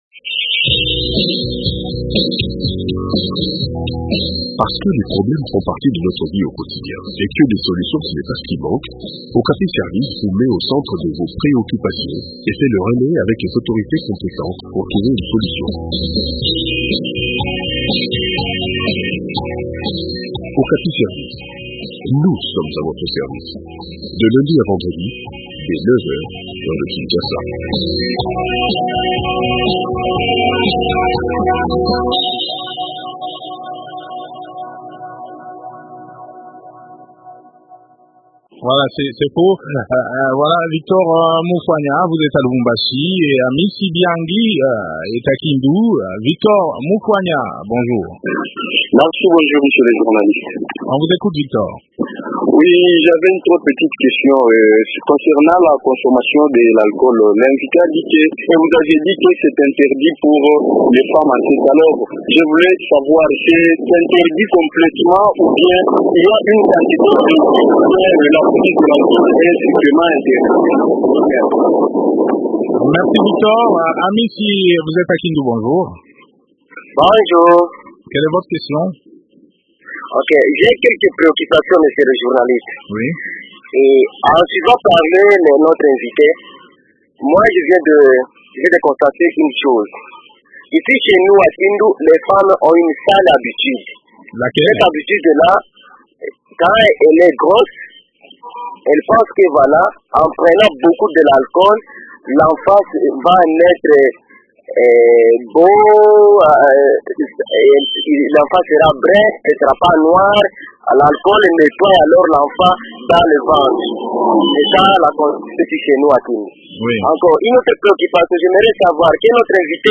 journaliste et expert en communication.